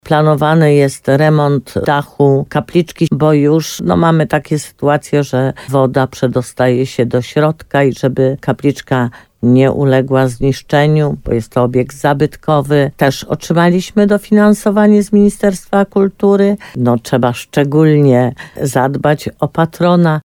Zagrożone zniszczeniem jest wnętrze kapliczki – mówi burmistrz Jolanta Juszkiewicz.